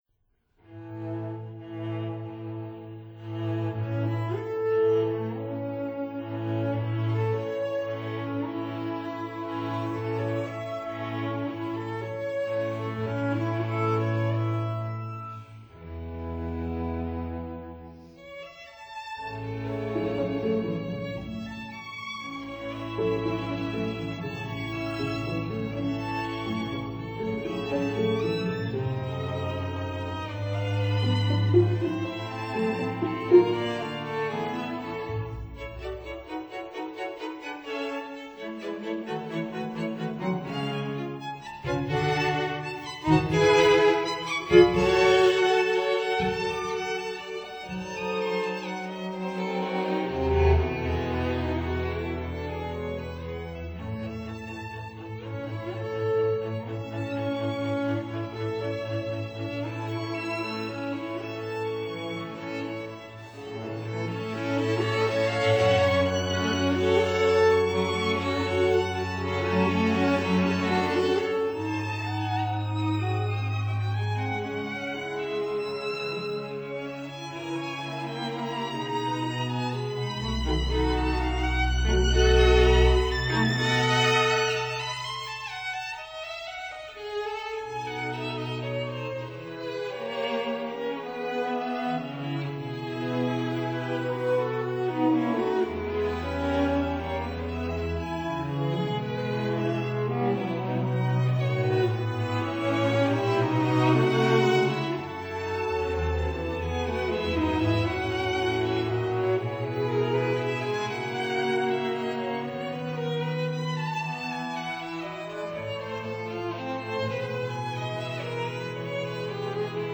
violin
viola
cello
double bass